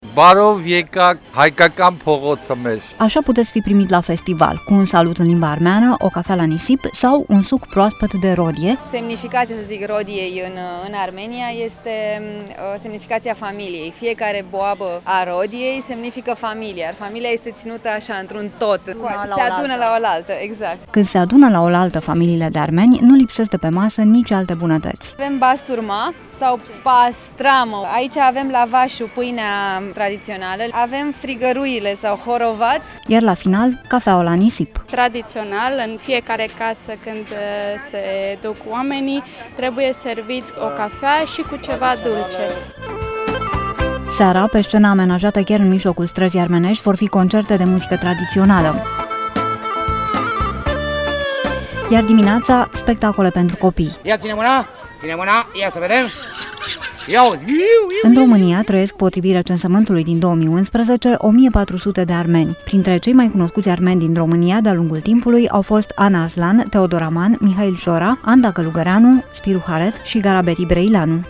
reportaj-strada-armeneasca.mp3